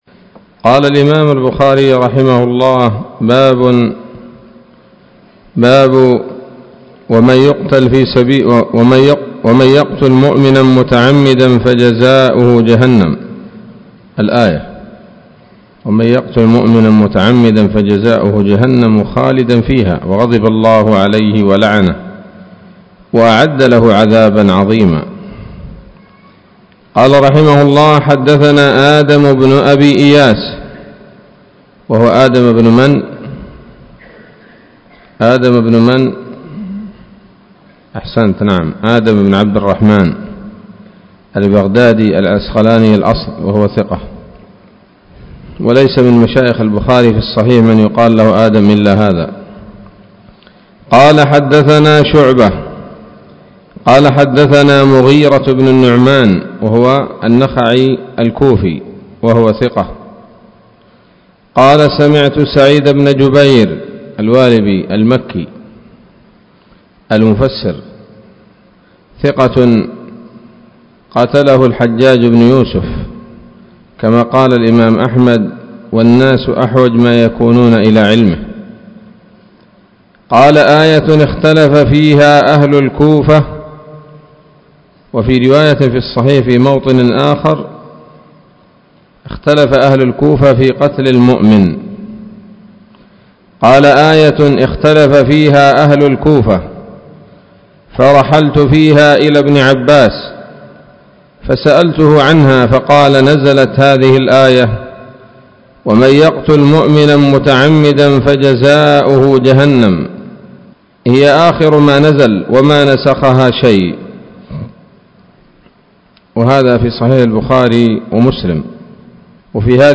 الدرس السادس والسبعون من كتاب التفسير من صحيح الإمام البخاري